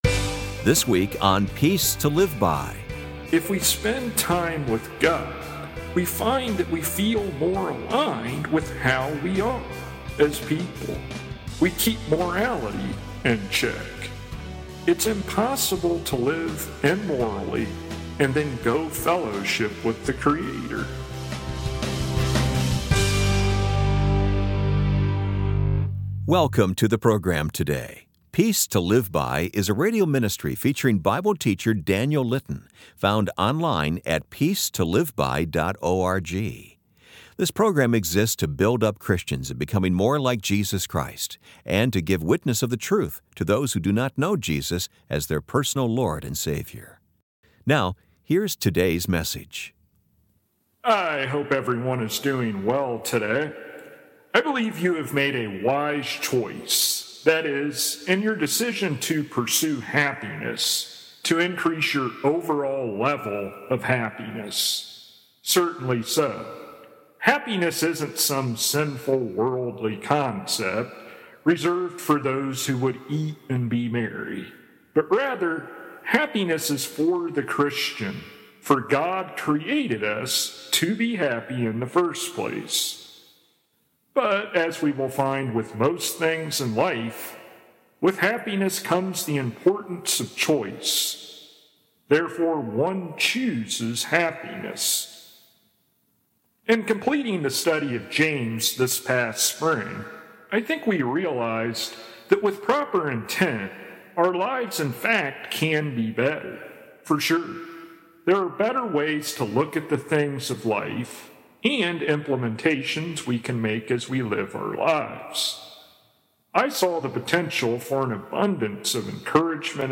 [Transcript may not match broadcasted sermon word for word] I hope everyone is doing well today.